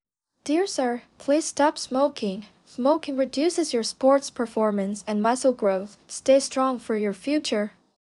10-19 male.wav